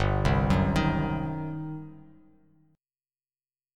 GM7sus4#5 chord